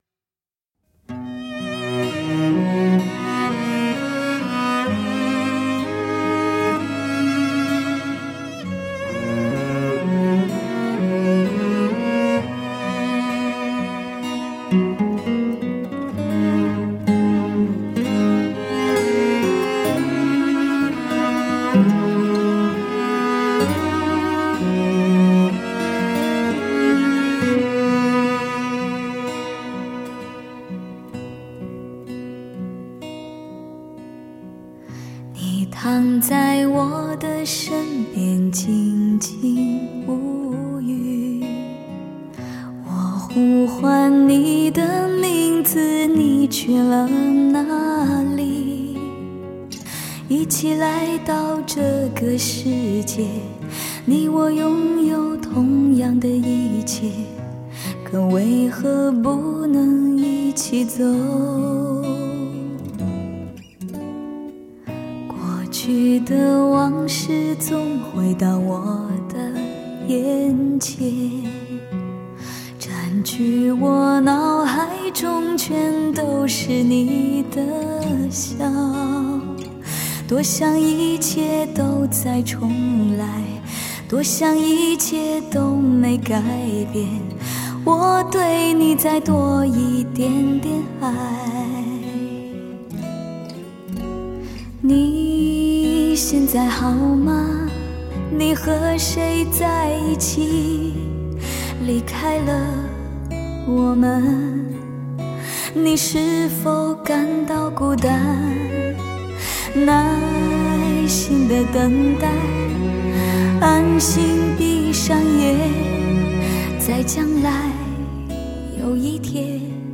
让轻轻的旋律和浓浓的咖啡一起驻入心田。